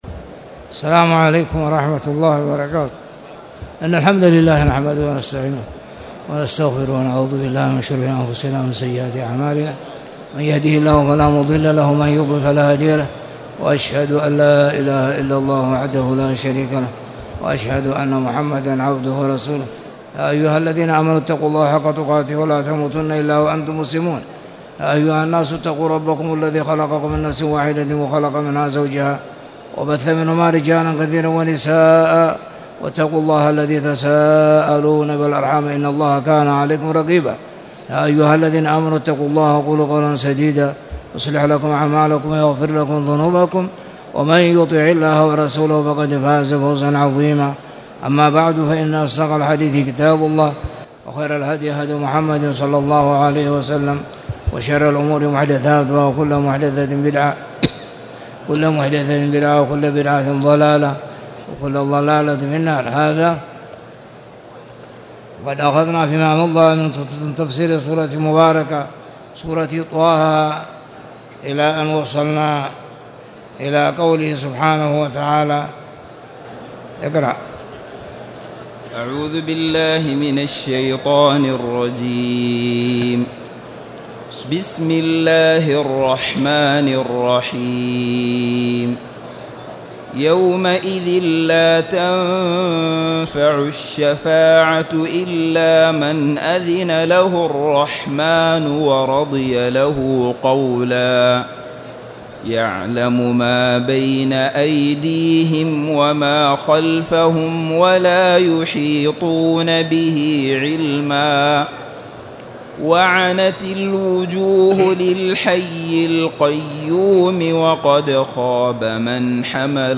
تاريخ النشر ٩ رجب ١٤٣٨ هـ المكان: المسجد الحرام الشيخ